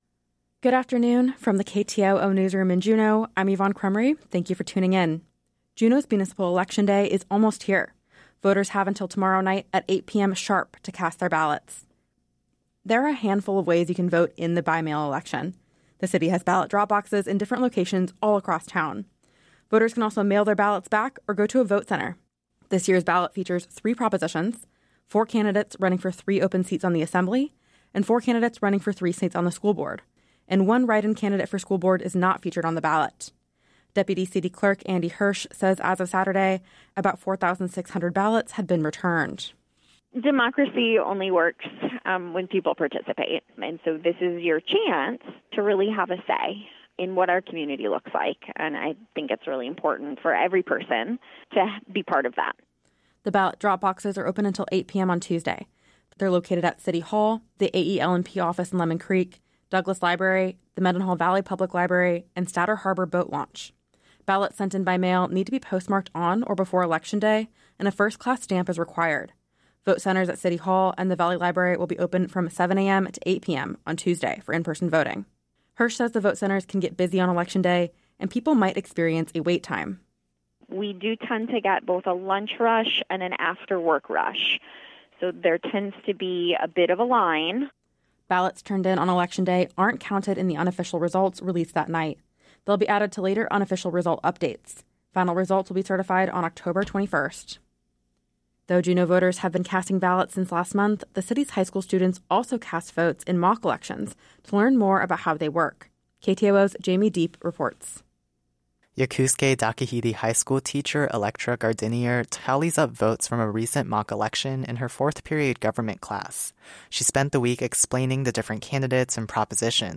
Newscast – Monday, Oct. 6, 2025 - Areyoupop